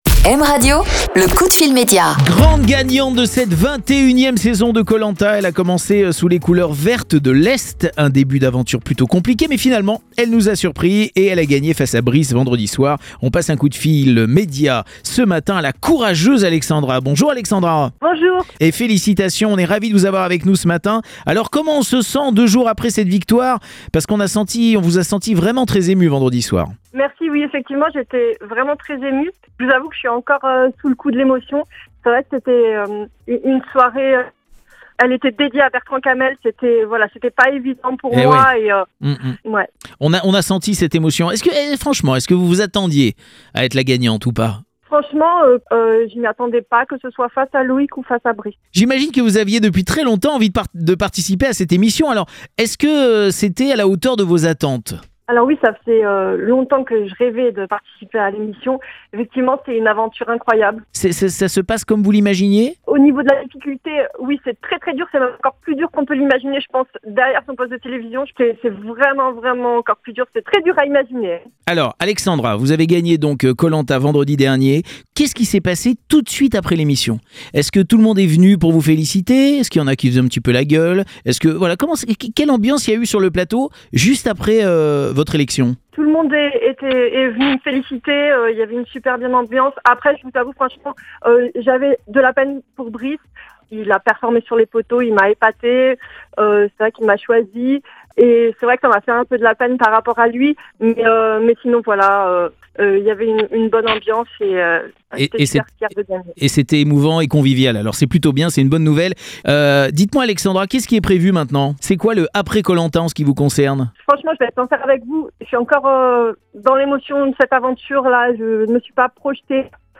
Alexandra la gagnante de Koh Lanta était ce matin en coup de fil Média avec Jerôme Anthony sur M Radio